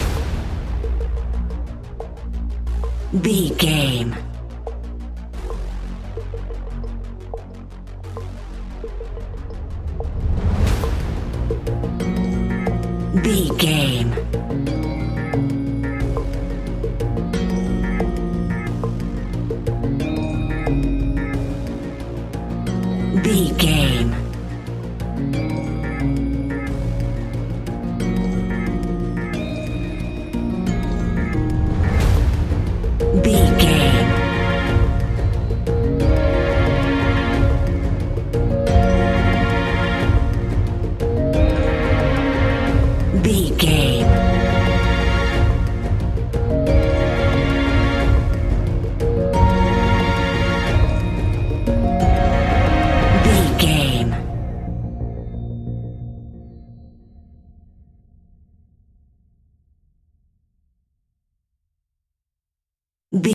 Scary Background Industrial Music 60 Sec.
Aeolian/Minor
A♭
ominous
eerie
synthesizer
strings
horror music